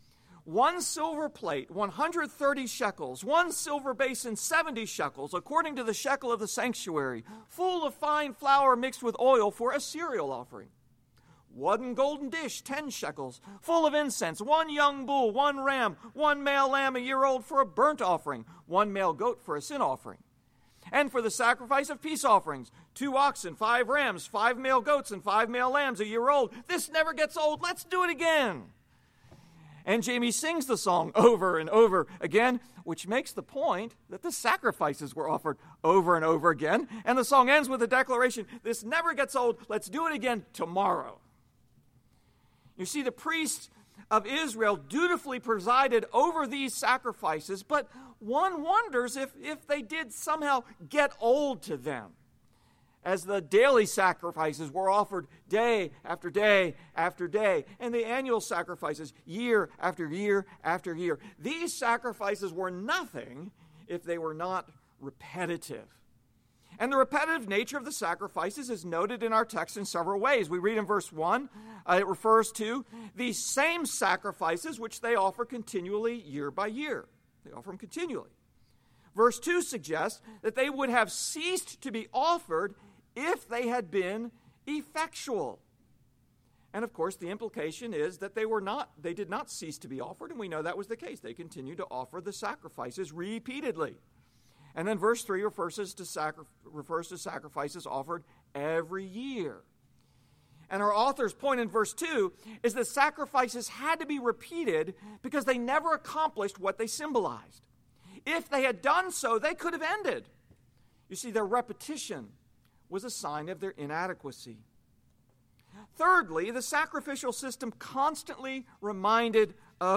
The sacrifices of the Old Covenant have come to an end, because in Jesus sacrifice has reached its end. [NOTE: first portion of sermon was not recorded]